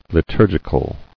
[li·tur·gi·cal]